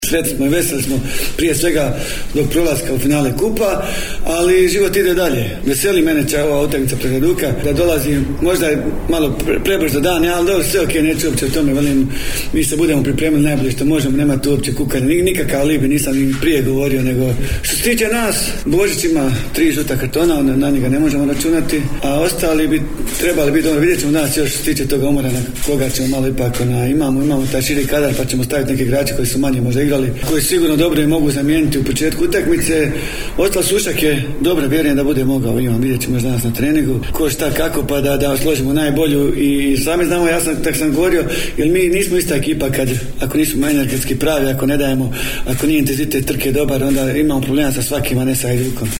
na konferenciji za medije